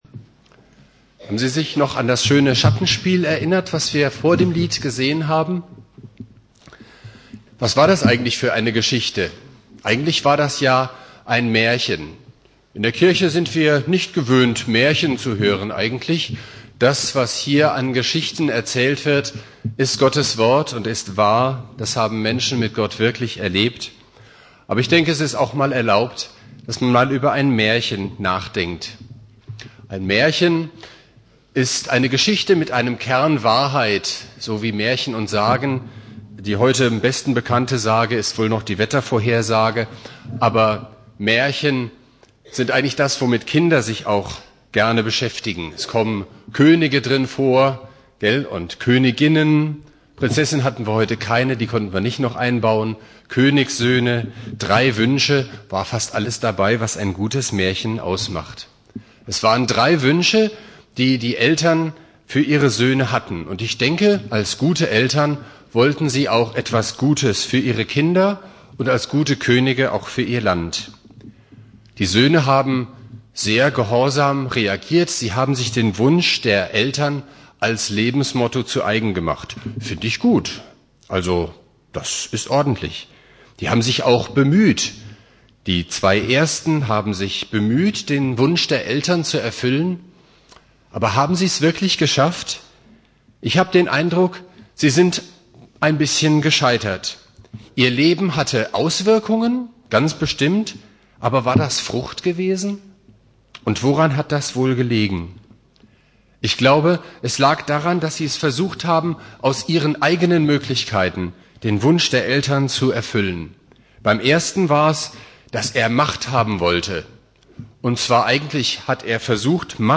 Predigt
Thema: Erntedank - Familiengottesdienst Bibeltext: Jeremia 17,7-8 Dauer